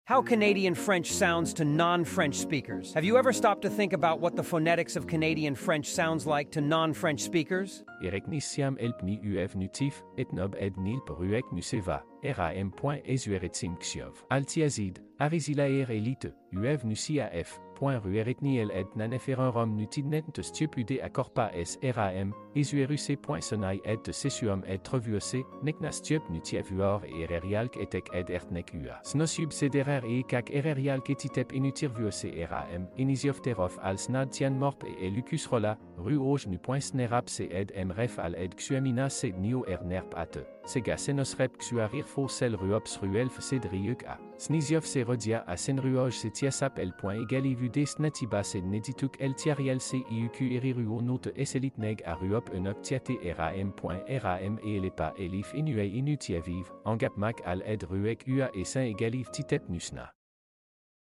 How Canadian French sounds to non-French speakers?